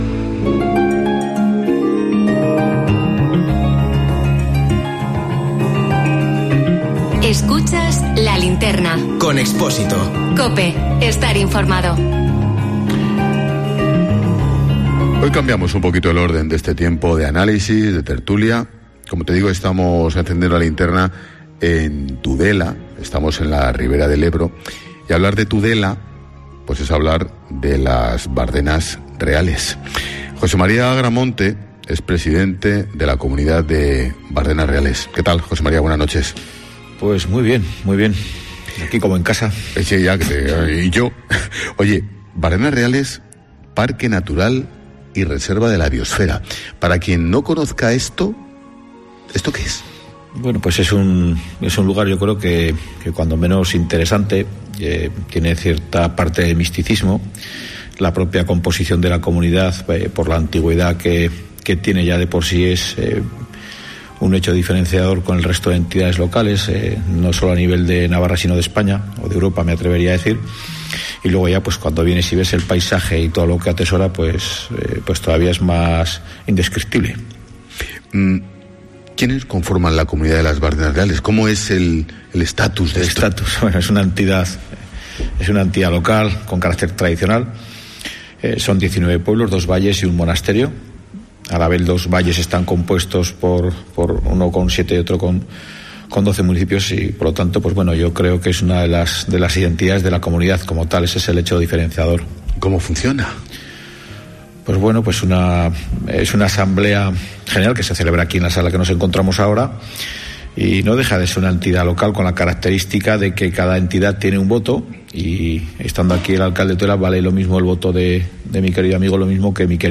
AUDIO: ENTREVISTA CON EL PRESIDENTE DE BARDENAS JOSÉ Mª AGRAMONTE